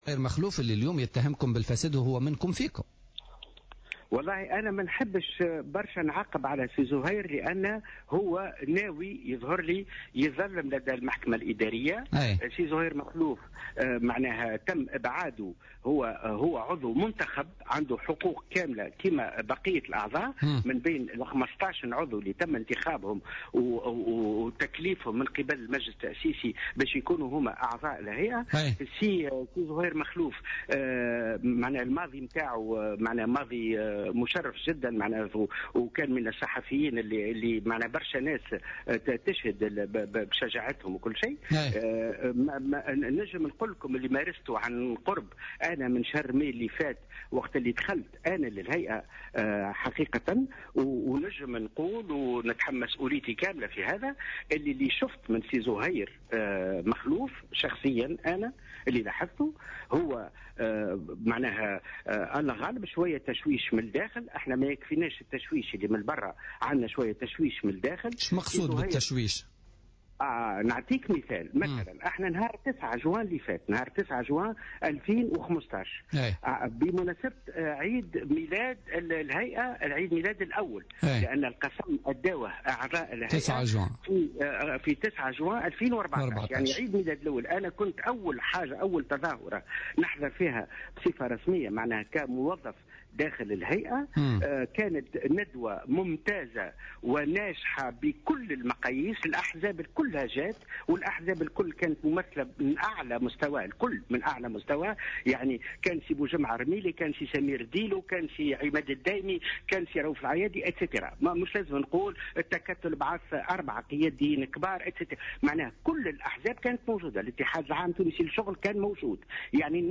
أكد أنور معلى عضو هيئة الحقيقة والكرامة في مداخلة له في بوليتيكا اليوم الخميس 10 سبتمبر 2015 أن التشويش على عمل الهيئة هو الذي يعطل سير أعمالها واضطلاعها بالمهام التي أنيطت بعهدتها.